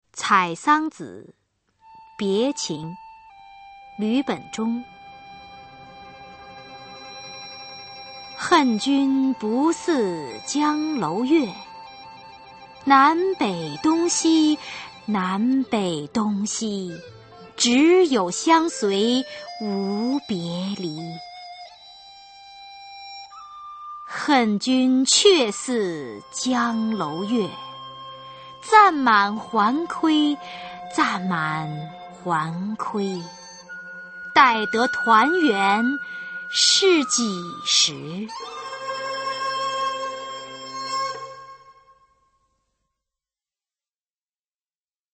[宋代诗词朗诵]吕本中-采桑子别情 古诗词诵读